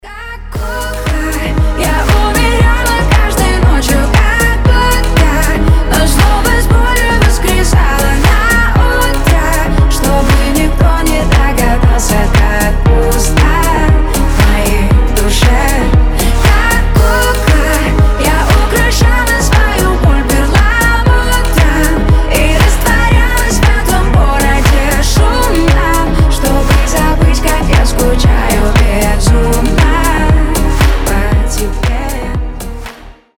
• Качество: 320, Stereo
женский вокал
грустные
попса